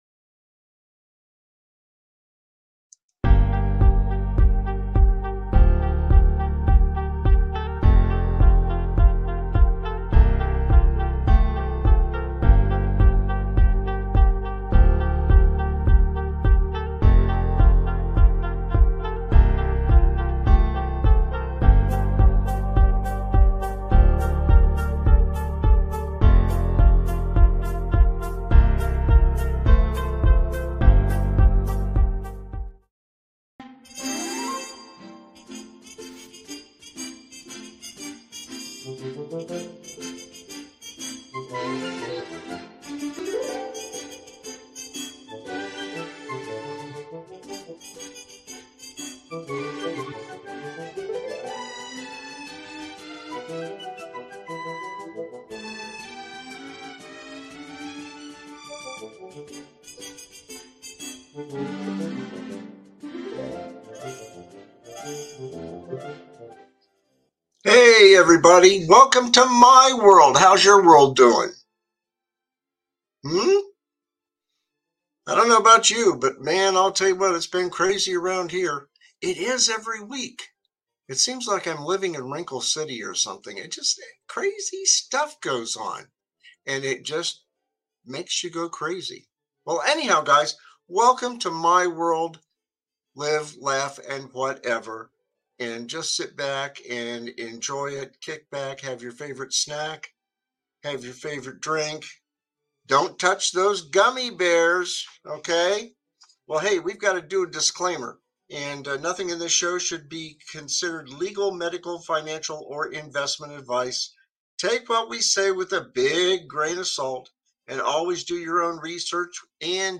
My World Live, Laff, Whatever is a satirical talk show that tackles the absurdities of life with a healthy dose of humor.
No topic is off-limits, and his guests, a rotating cast of comedians, commentators, and everyday folks, add their own unique perspectives to the mix. Get ready for sharp wit, offbeat takes, and side-splitting commentary.&nbsp